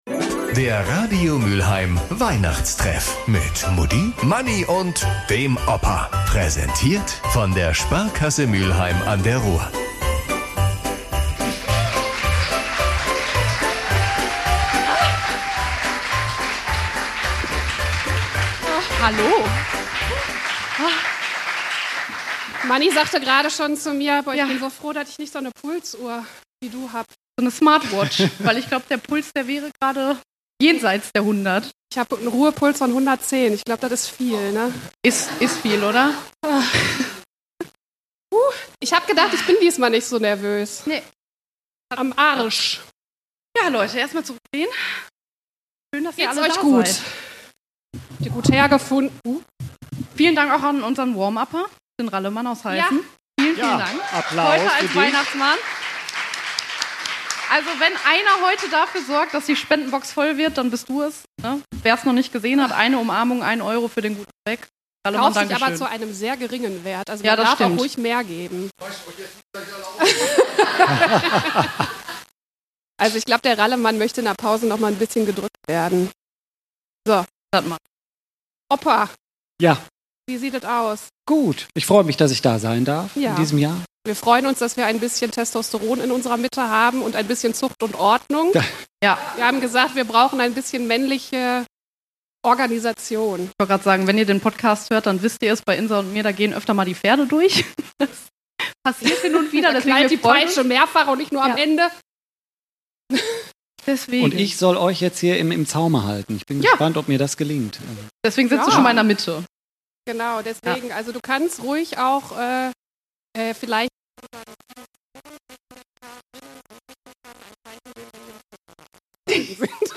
Der Radio Mülheim Weihnachtstreff live Teil 1
Beschreibung vor 1 Jahr Gefühlt hat der Heiligabend schon stattgefunden: Am 12. Dezember, im Rittersaal vom Schloss Broich.